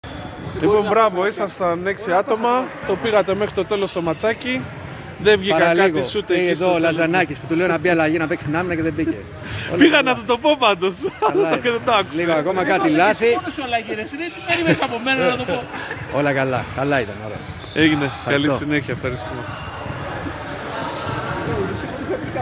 GAME INTERVIEWS
Παίκτης SAB